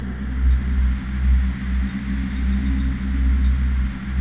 ATMOPAD33 -LR.wav